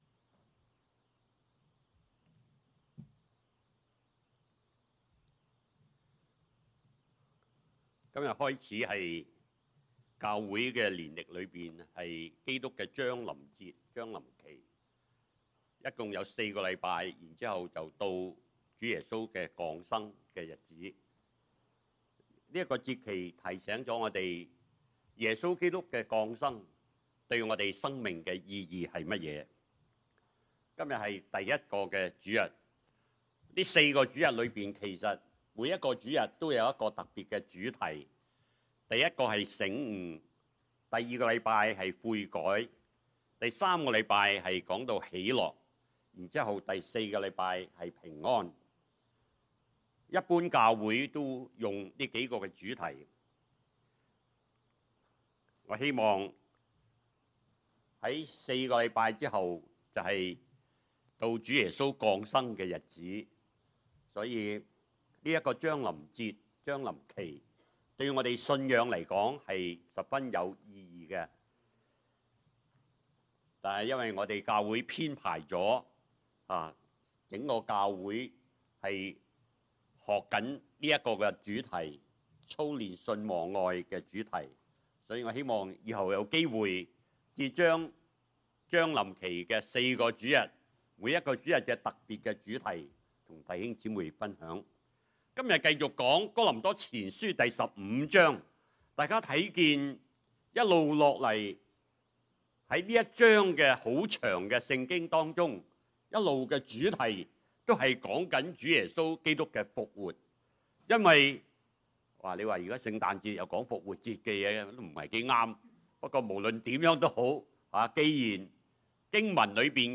Hello, I would like to share a good sermon with you. Title: 死阿！你得勝的權勢在那裏？